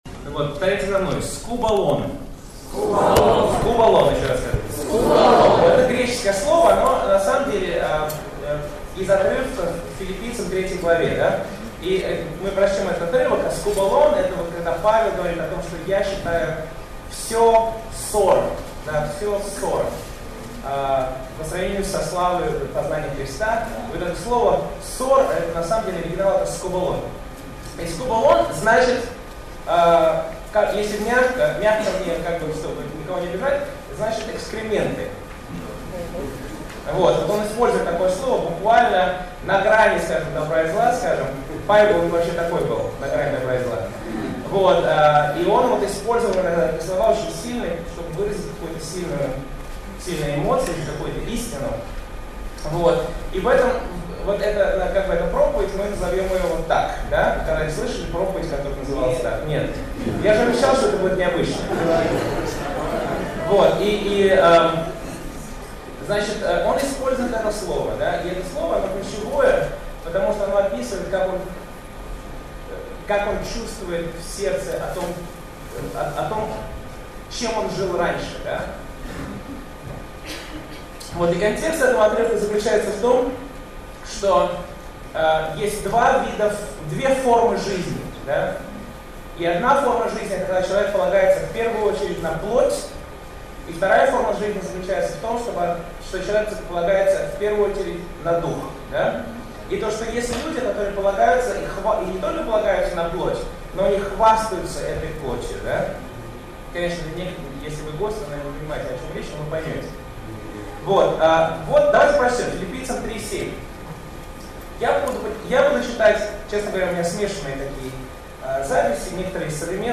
Все почитаю за сор - Другие - Аудиопроповеди - Каталог статей - Библия Online
Воскресная служба